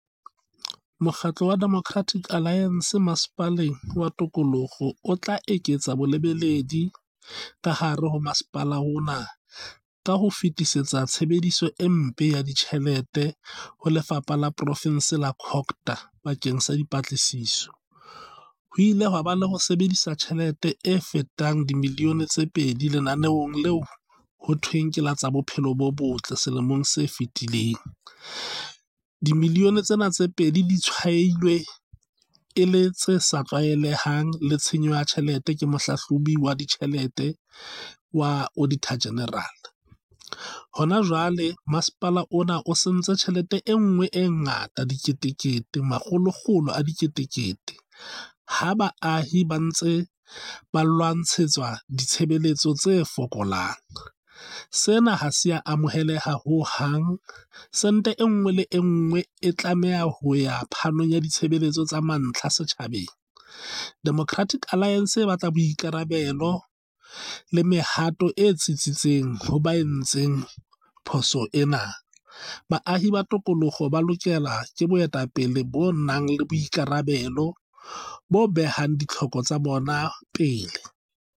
Sesotho soundbites by Cllr Hismajesty Maqhubu.